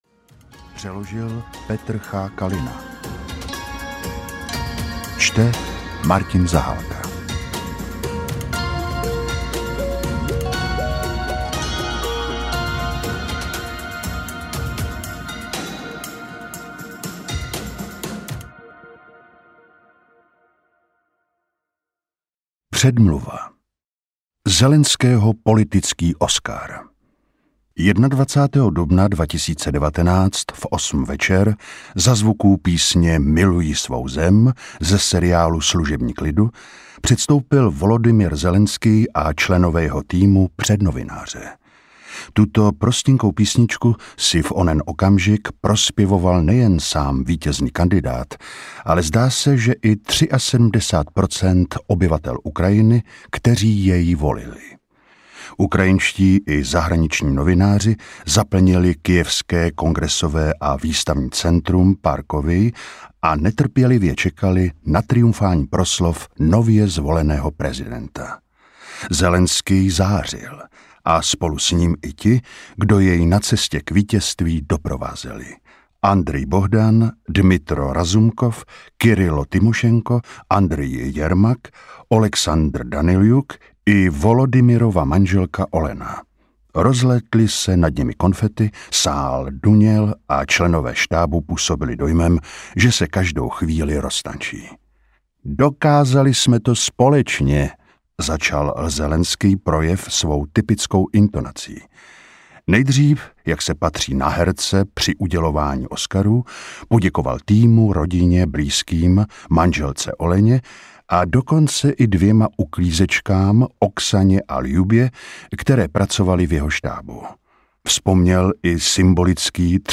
Volodymyr Zelenskyj audiokniha
Ukázka z knihy